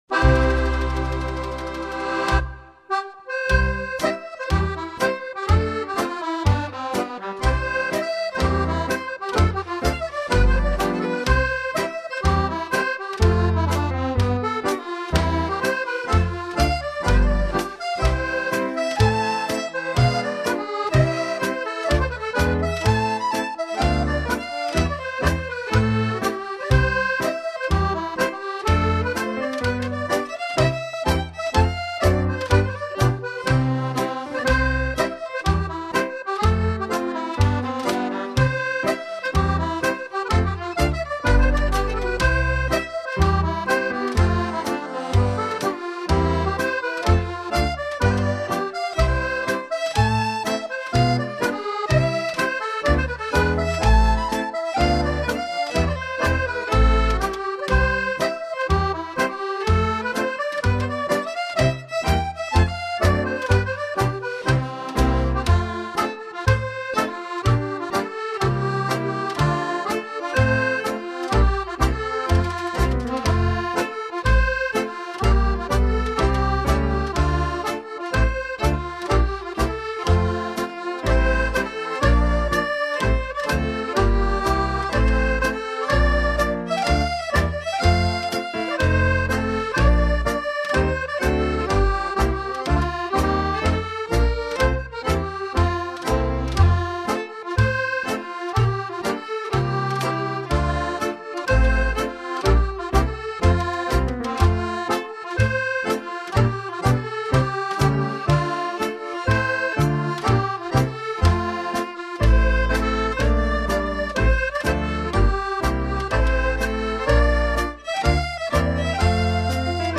A strathspey